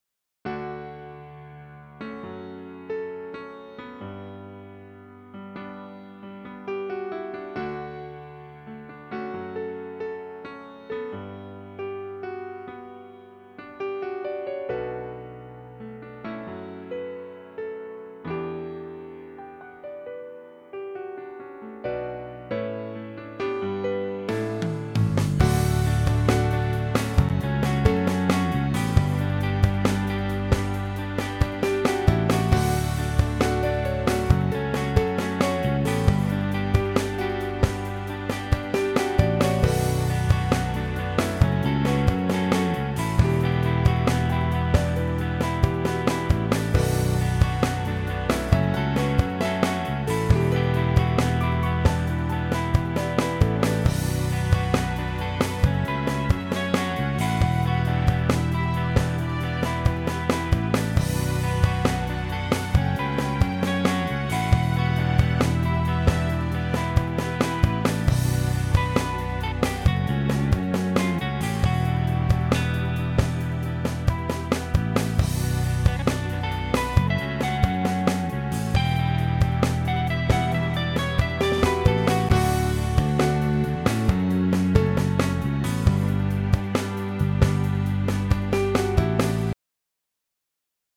Note that I haven't really bothered making sure that the bounces are great, so there are a few clicks here and there.
But I don't really know -- it needs a lot of work, particularly in the guitar line, in my opinion.